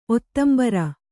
♪ ottambara